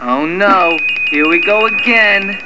Note: All of these sounds are spoken by the cartoon characters, NOT the real guys, unless otherwise noted.
an NKOTB cartoon